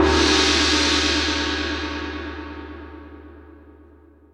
Crashes & Cymbals
Cymbal 1.wav